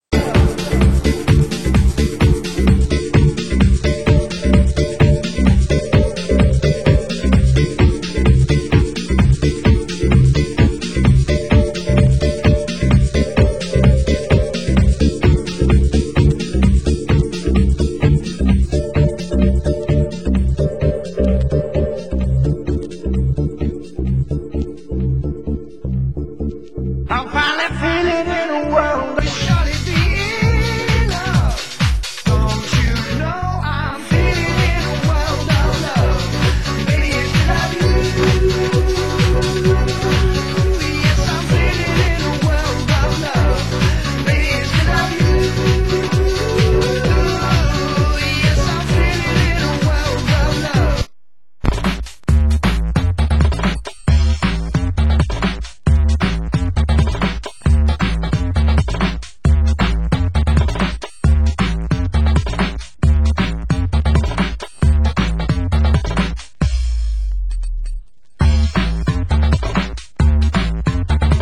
Genre: Progressive
club vocal